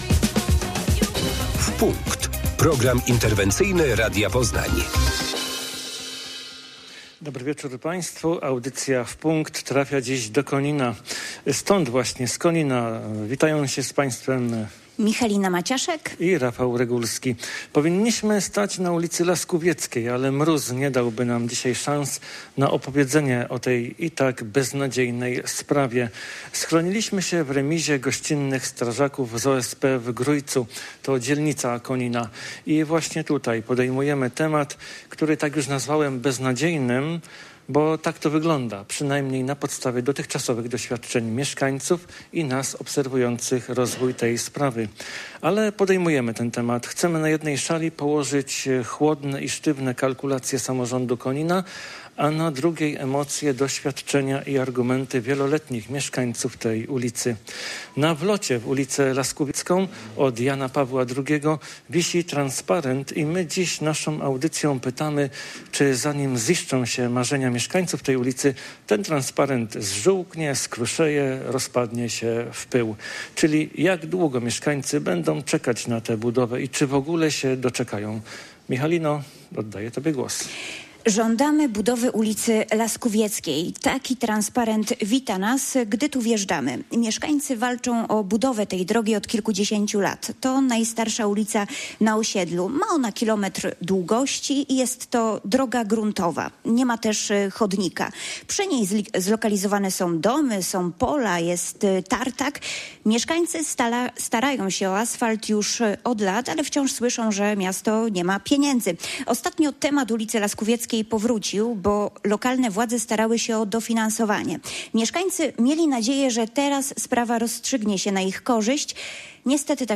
30 mieszkańców ulicy Laskówieckiej w Koninie wzięło udział w naszej kolejnej, nadawanej na żywo, audycji interwencyjnej, pt. "W punkt". Mieszkańcy od kilkudziesięciu lat żądają budowy swojej ulicy, która jest drogą gruntową, niemającą chodników.